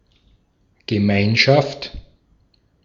Ääntäminen
Synonyymit people jaghir local administrative unit Ääntäminen US CA Tuntematon aksentti: IPA : /k(ə)ˈmjunəti/ IPA : /k(ə)ˈmjunəɾi/ Lyhenteet ja supistumat (laki) Cmty.